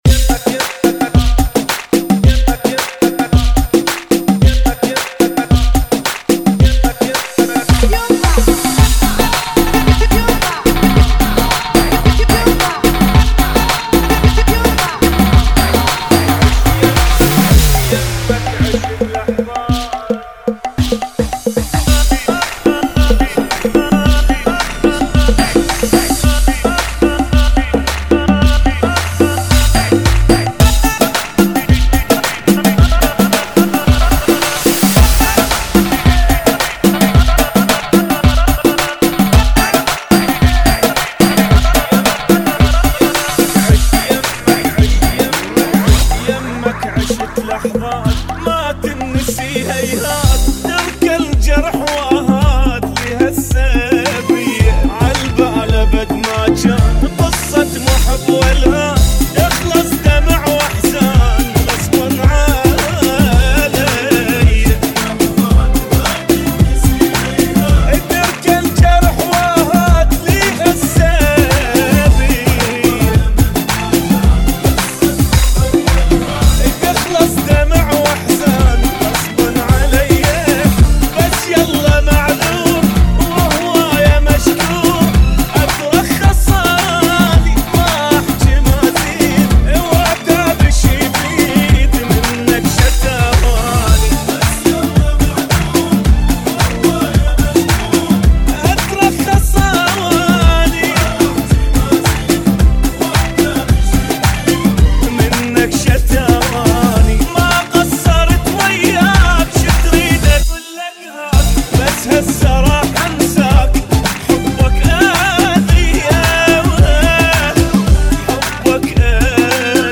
[ 110 Bpm ]
Remix